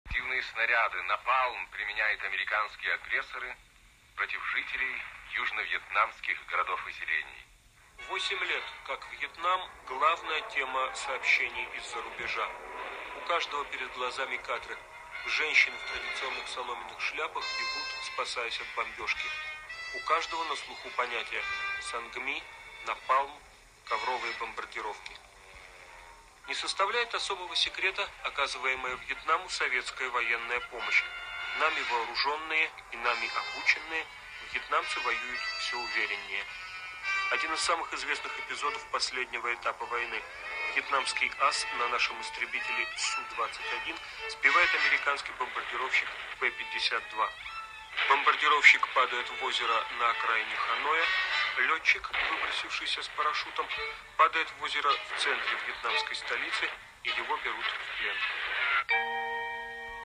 Документальный фильм
Друзья!  Помогите, пожалуйста, найти документальный фильм, в котором этот текст читает Леонард Хмара: "Реактивные снаряды, напалм применяют американские агрессоры против жителей южновьетнамских городов и селений"